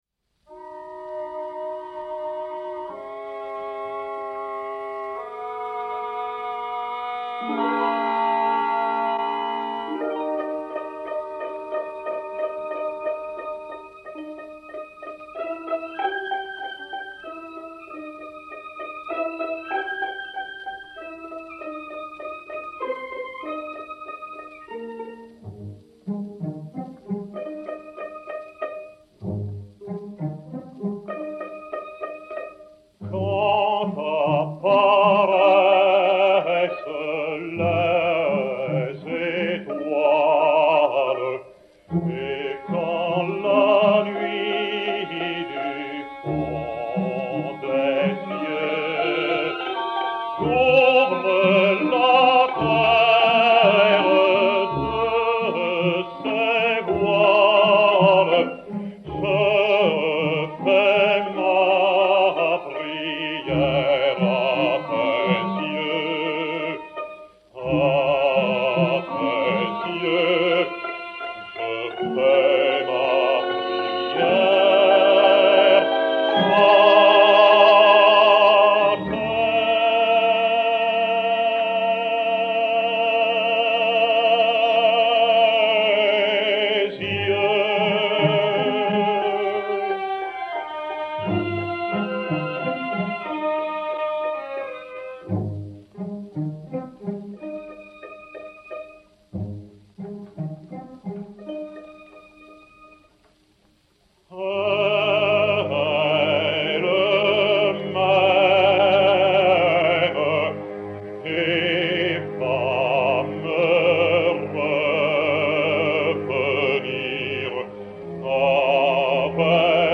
Sérénade "Quand apparaissent les étoiles"
Vanni-Marcoux (Don Quichotte) et Orchestre dir Piero Coppola